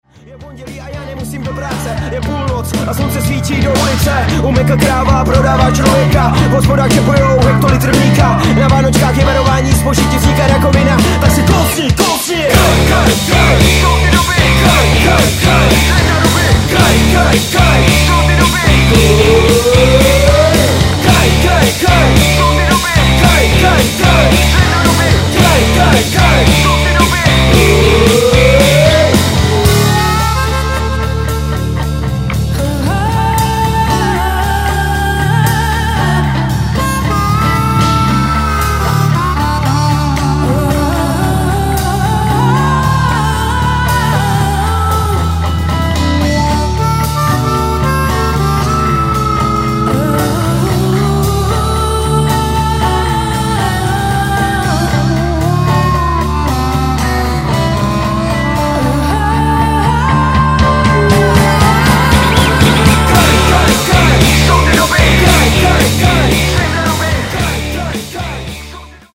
rap blues